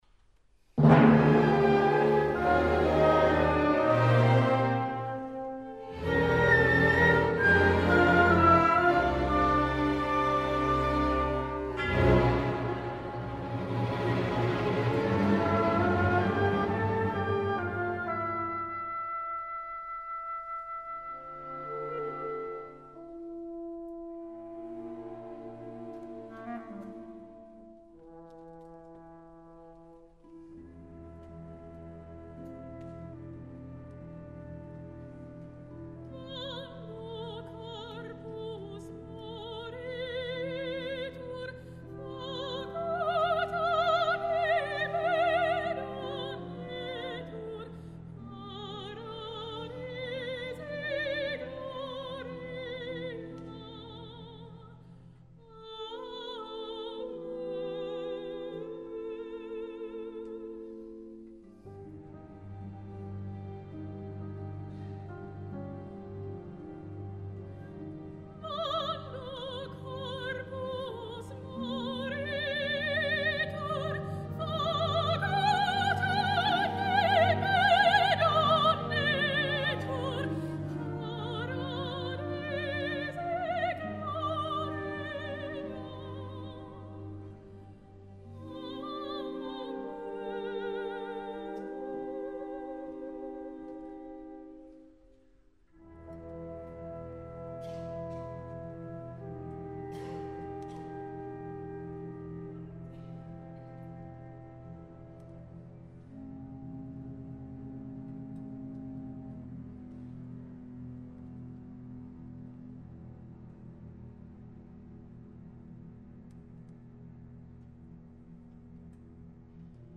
4. Orchester, Solo, Schlusschor abT. 573 » hören